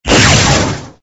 lightning_3.ogg